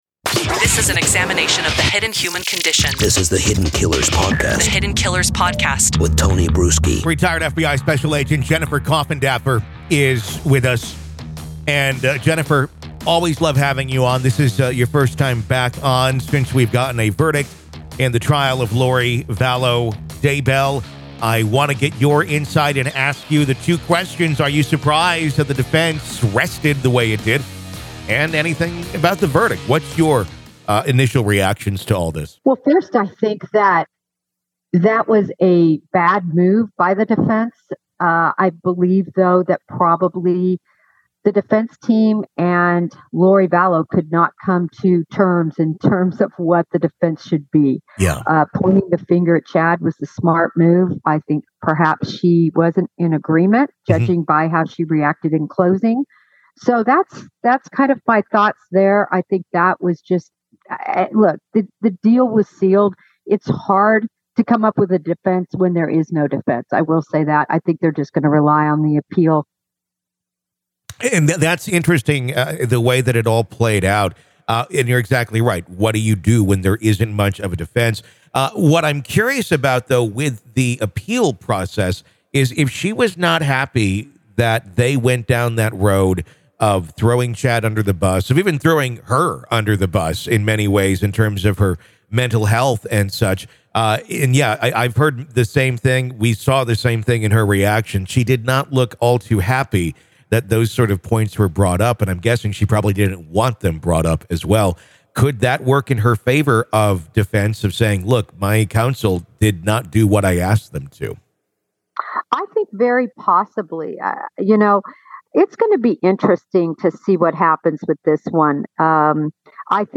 She also explores the possible factors that swayed the jury's decision in this complex and high-profile murder case. Don't miss this riveting conversation filled with expert insights and perspectives.